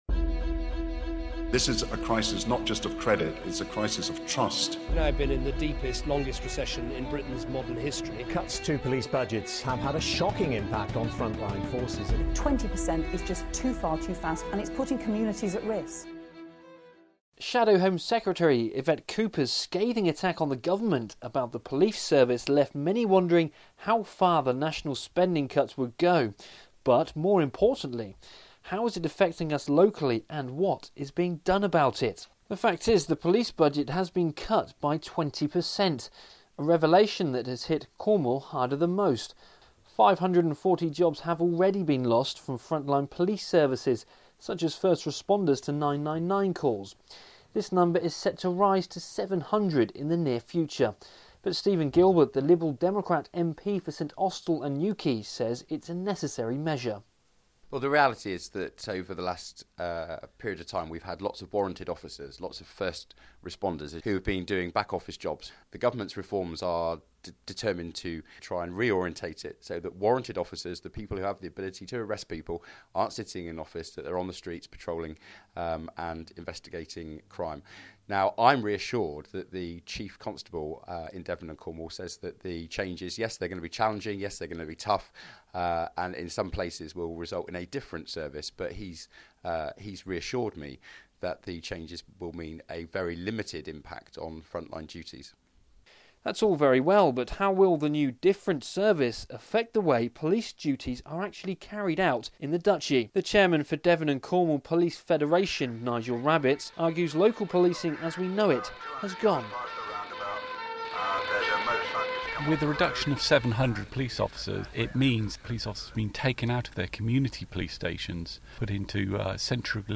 Radio package on impact the police cuts will have on Cornwall